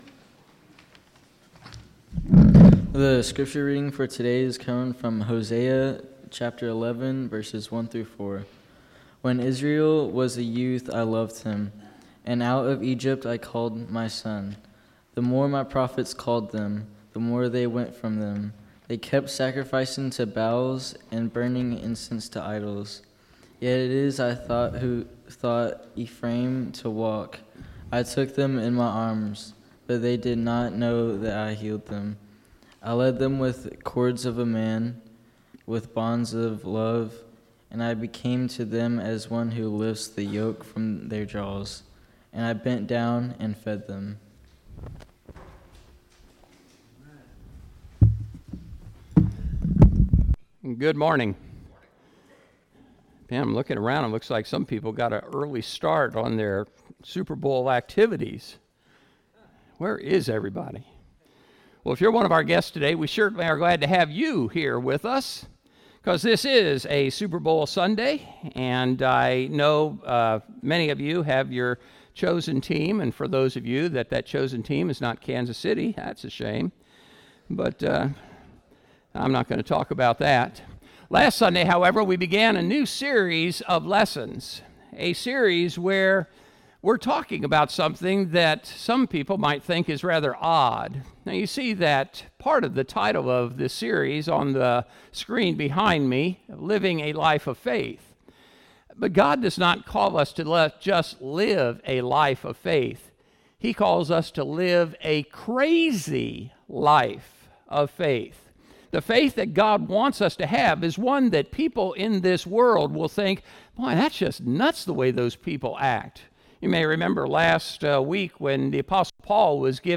Sermon: Blessed are the Pure in Heart: Lesson #4: Stategies for Resisting Moral Compromise